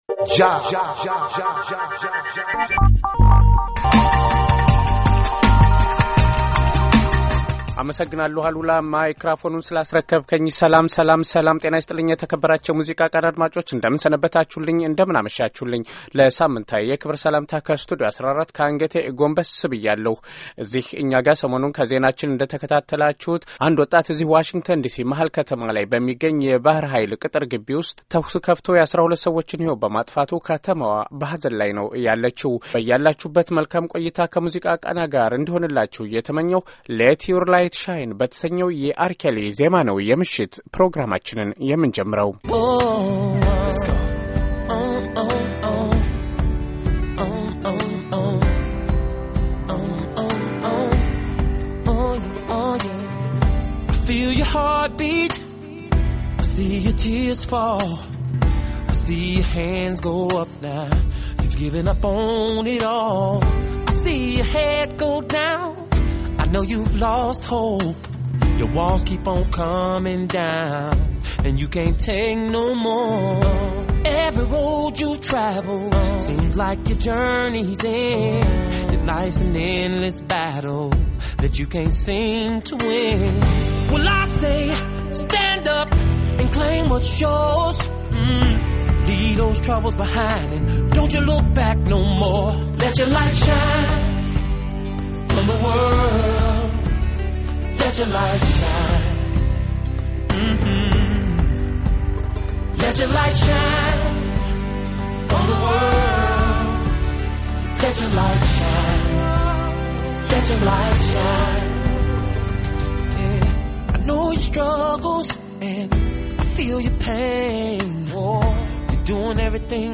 የዛሬው የሙዚቃ ቃና ፕሮግራም የበርካታ ድምጻውያንን ዜማዎች እና ሙዚቃ ነክ ዜናዎችን ያስተናግዳል፡፡